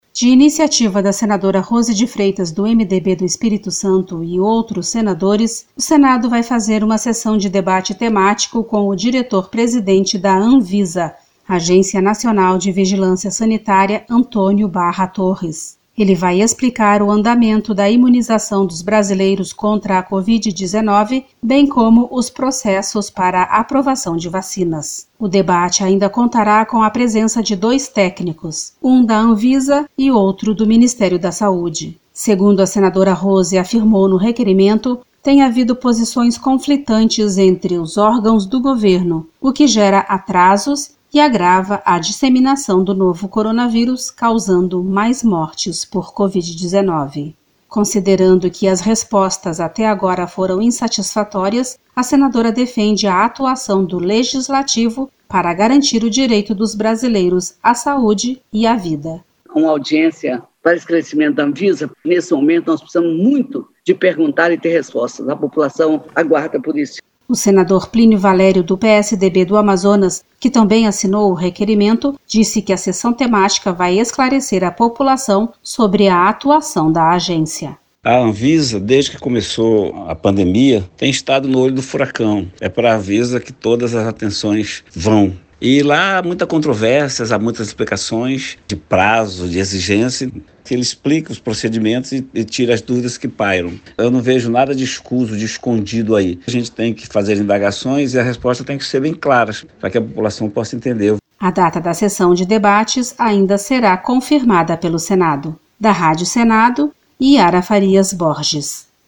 O requerimento para a realização desse debate foi apresentado pela senadora Rose de Freitas (MDB-ES). A reportagem